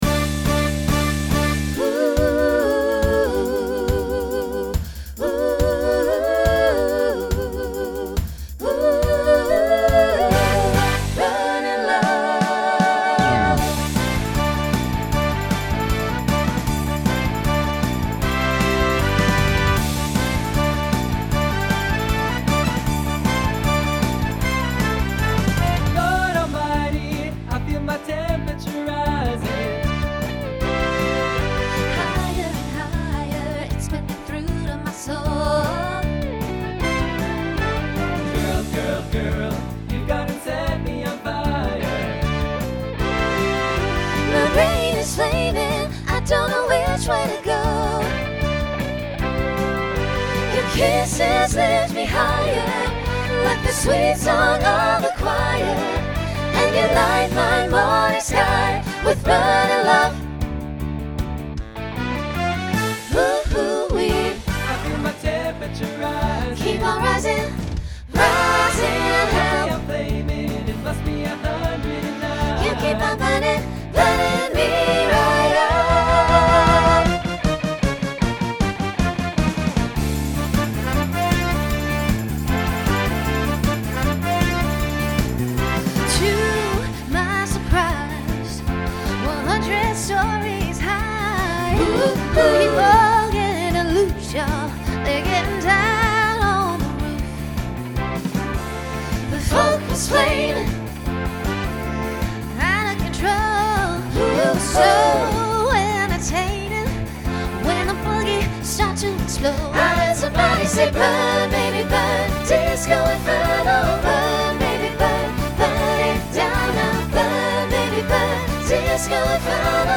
Genre Disco , Rock Instrumental combo
Voicing SATB